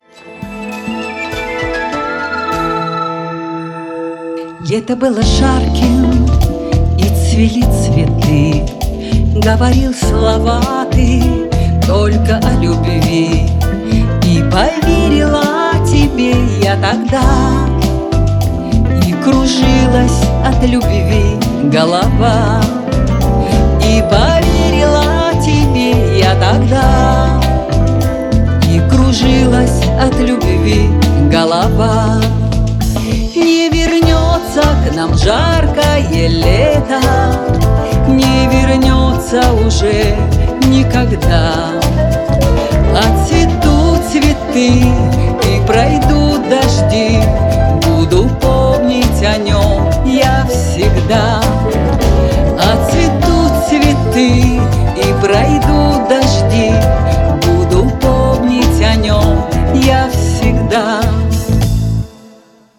Сочинила она очень незатейливую песенку с простеньким текстом,простенькой мелодией.